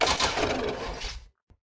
car-no-fuel-2.ogg